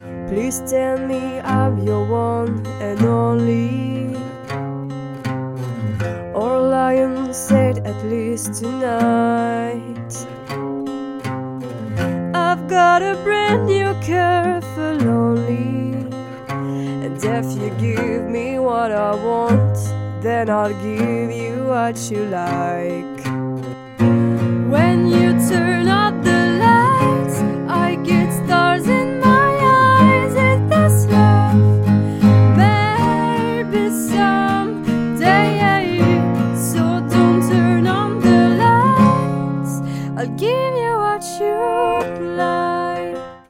Avant le studio...